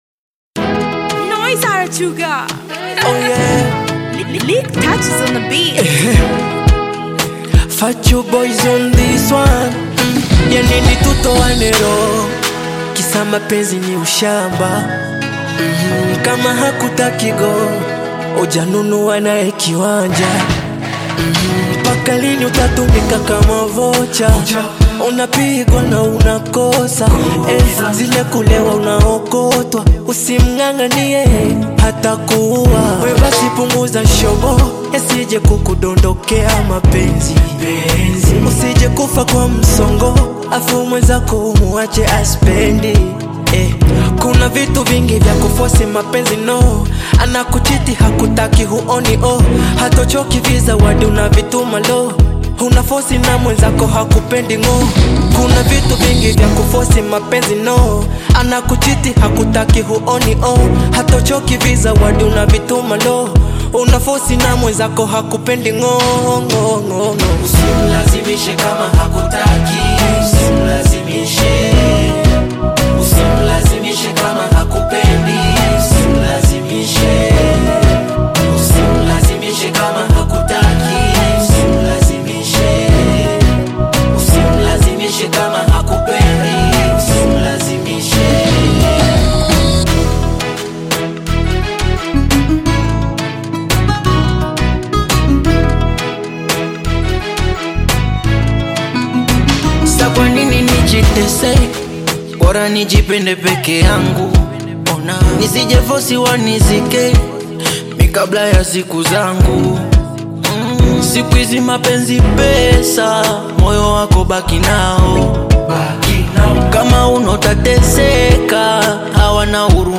Tanzanian bongo flava group